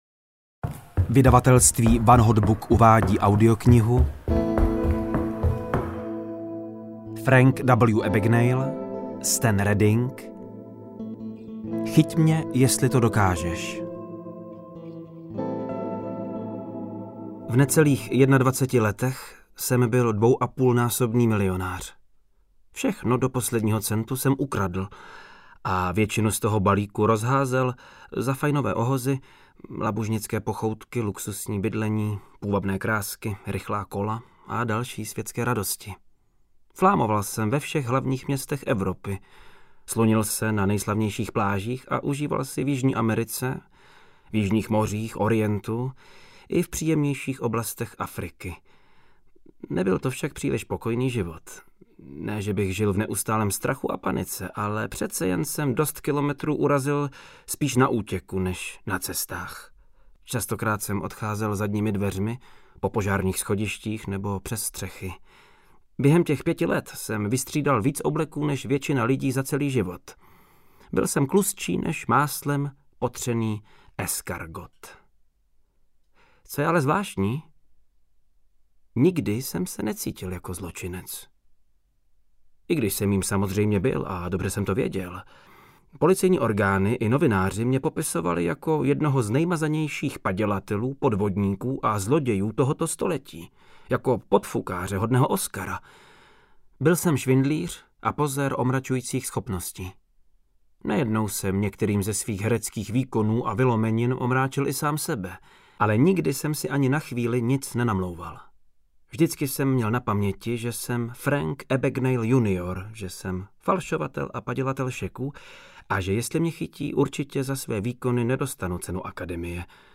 Chyť mě, jestli to dokážeš audiokniha
Ukázka z knihy
• InterpretVáclav Neužil